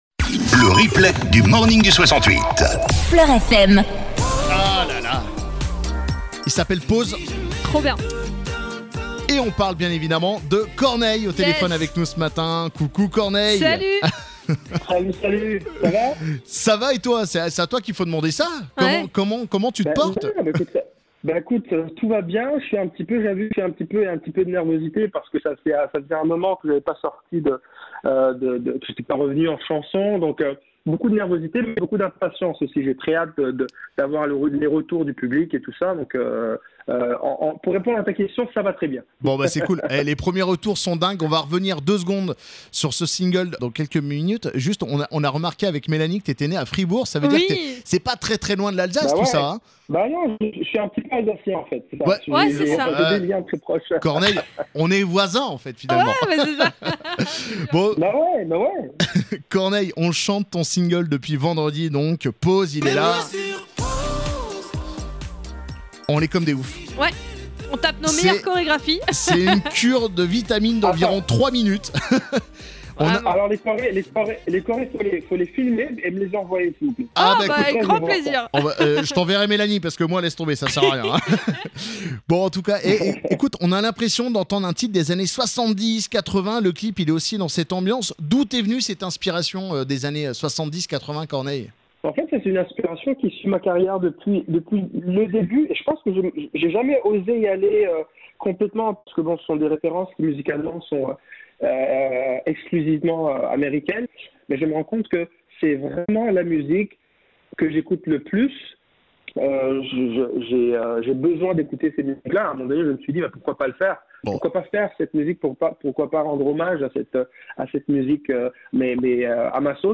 LeMorningDu68 FLOR FM Mardi 11 Mai 0:00 9 min 21 sec 11 mai 2021 - 9 min 21 sec CORNEILLE DANS LE MORNING DU 68 Corneille est de retour en 2021 avec un nouveau single "Pause", qu'il nous présente ce matin dans Le Morning du 68. Il nous parle également de son nouvel album "Encre Rose" disponible à l'automne.